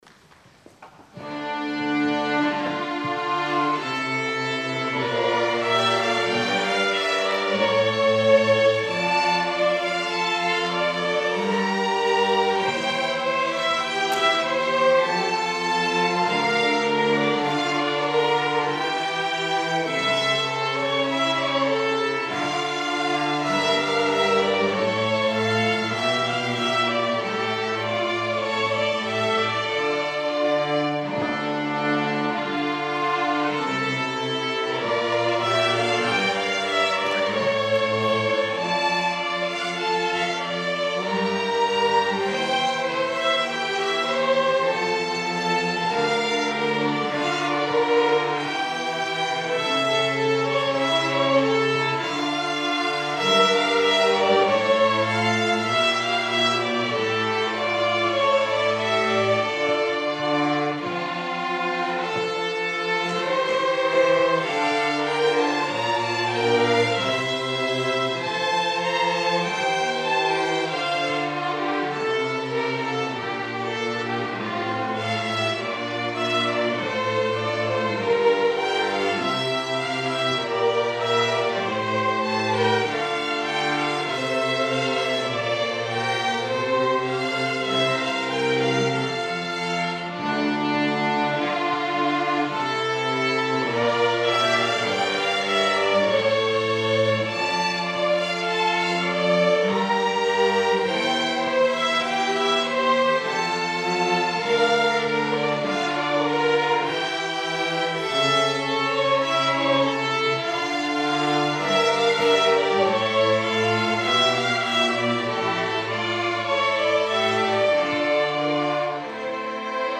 Muzikale opening Strijkorkest